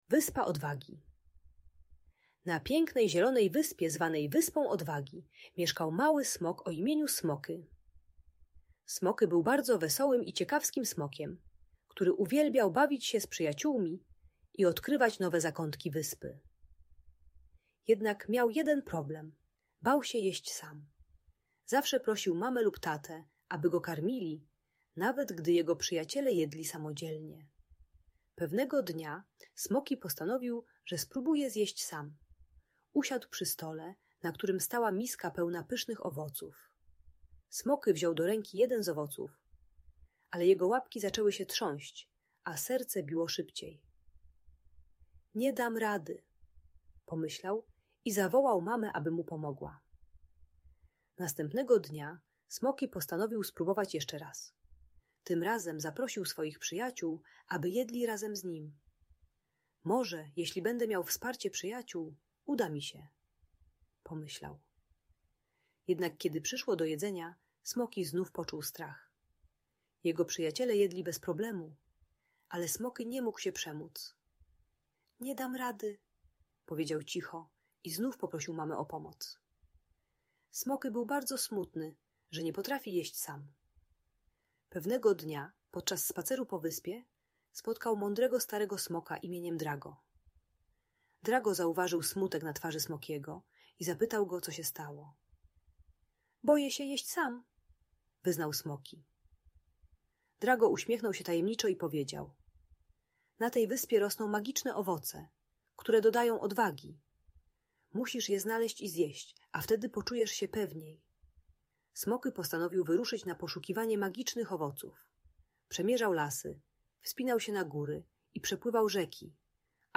Smoky na Wyspie Odwagi - Audiobajka